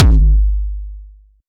puredata/resources/808_drum_kit/classic 808/Ramen.wav at master